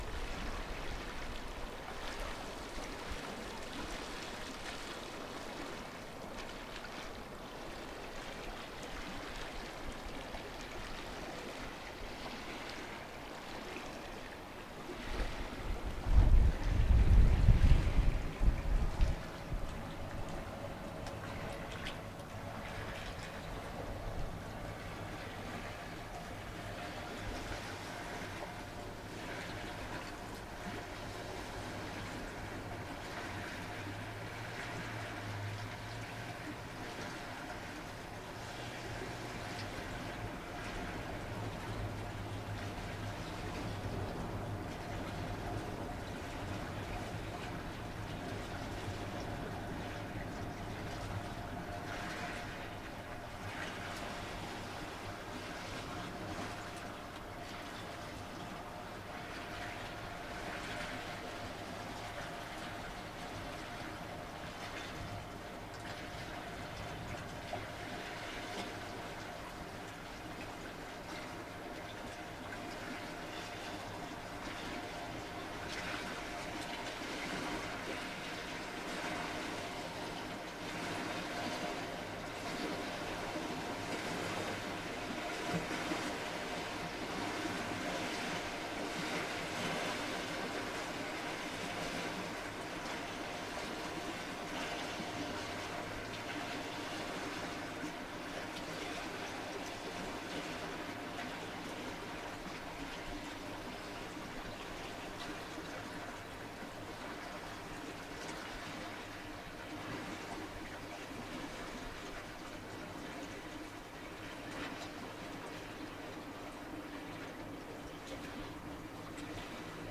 What is heard here was recorded inside the sculpture, Wave Sound (2017), a work that was intended by artist Rebecca Belmore to amplify the sound of the water at Lake Minnewanka.
The focused sound of the movement of water was extraordinary in a place where the visual senses are so flooded with the spectacularity of mountain vistas. This natural amplification of the lake through the sculpture established a sense of an aural connection to this body of water, while the physical act of sitting alongside the work was also a kind of bodily commitment to spend time.